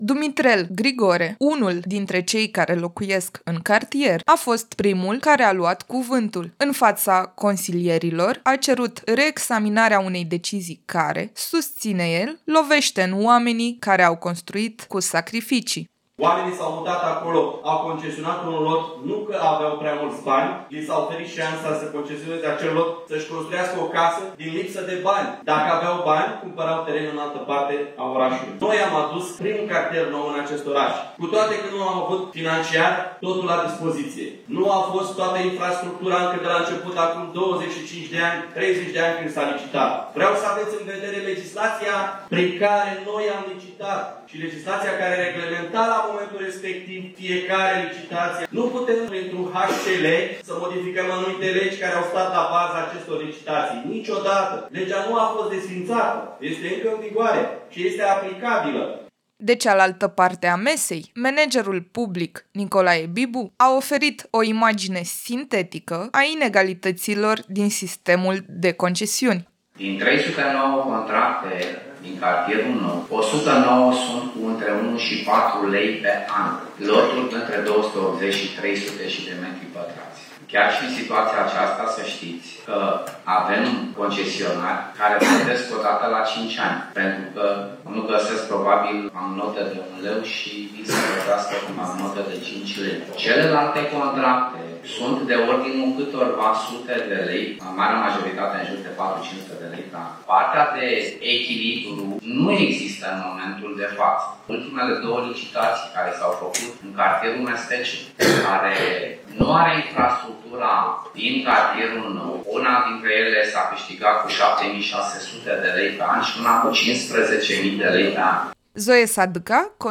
Ședința Consiliului Local de săptămâna trecută s-a ținut cu sala plină.
Discuția din Consiliul Local a fost tensionată, dar necesară.